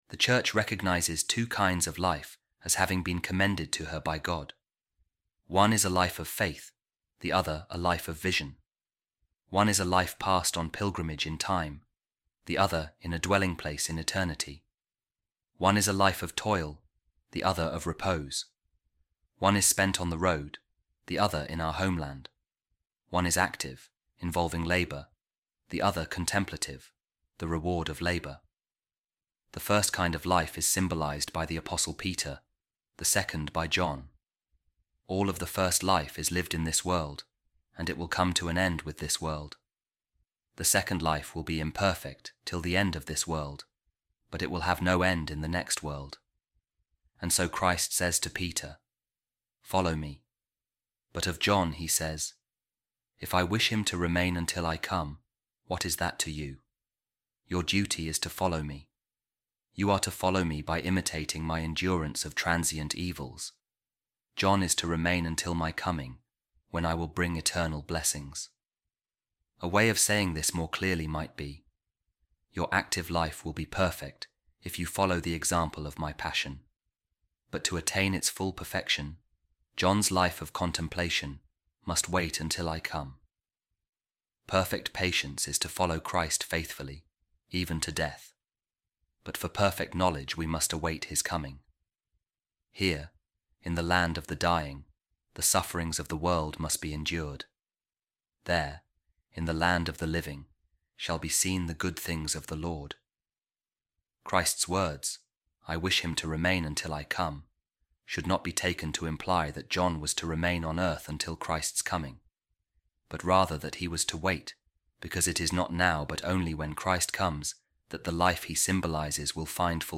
Office Of Readings | Eastertide Week 6, Saturday After The Ascension | A Reading From The Homilies Of Saint Augustine On Saint John’s Gospel | Two Kinds Of Christian Life